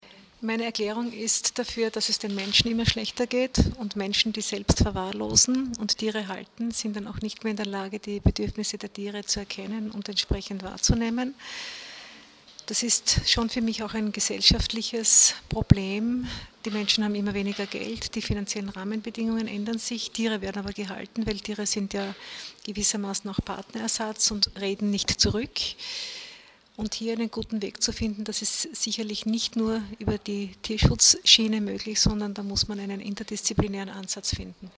Graz (5. Juni 2013).- Gemeinsam mit dem zuständigen Landesrat Gerhard Kurzmann präsentierte die steirische Tierschutzombudsfrau Barbara Fiala-Köck heute Vormittag (05.06.2013) im Medienzentrum Steiermark ihren aktuellen Tätigkeitsbericht.